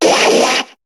Cri d'Akwakwak dans Pokémon HOME.